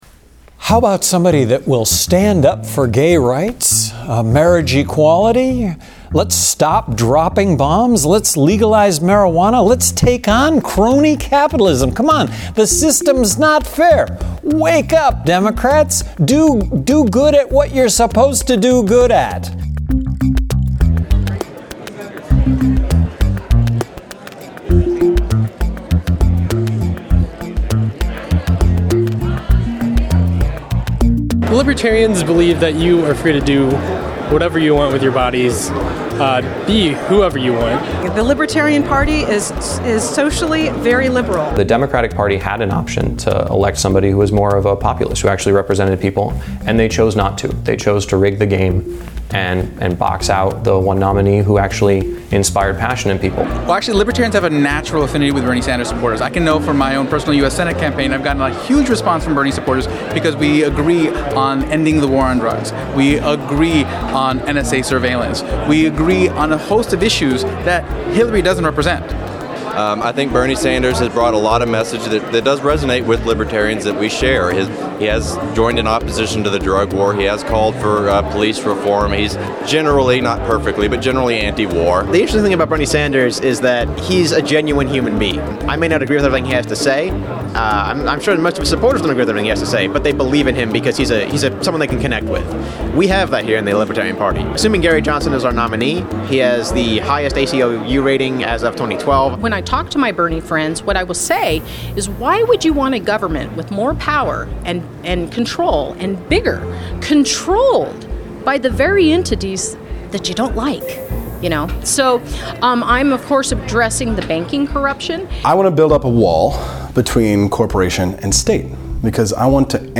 Reason TV asked presidential candidates and members of the Libertarian Party at the Libertarian National Convention in Orlando, Florida why Bernie-loving Democrats should abandon Hillary Clinton and vote Libertarian this year.